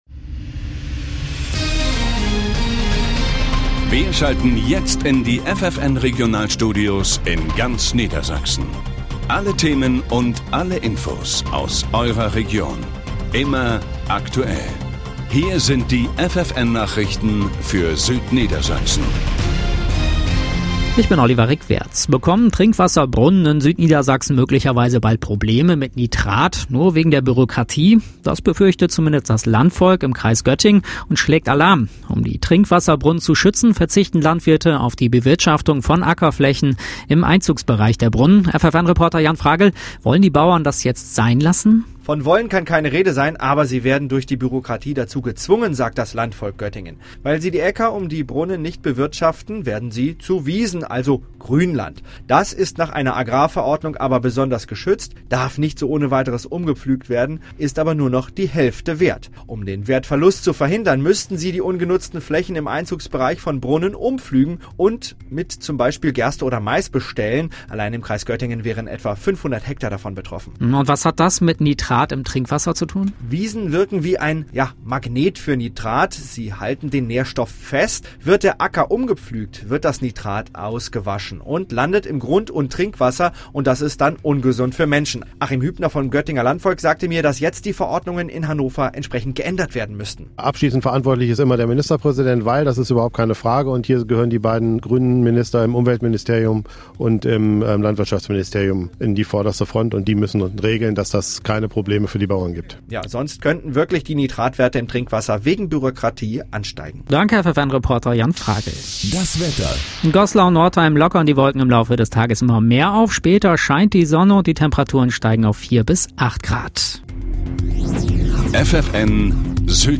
Bericht FFN vom 12.3.2015